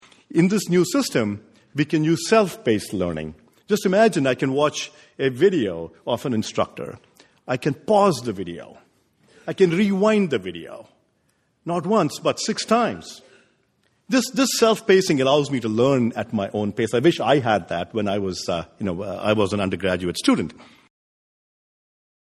Intelligence Squared hosted a debate on April 2, 2014, with the motion More Clicks, Fewer Bricks: The Lecture Hall is Obsolete. Anant Agarwal, CEO of edX and professor at MIT, argued for the motion.